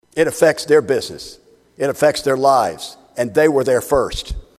REPUBLICAN REPRESENTATIVE STEVEN HOLT OF DENISON SAYS PEOPLE WHO DON’T WANT THE PIPELINE ON THEIR PROPERTY SHOULD HAVE THE RIGHT TO SAY NO.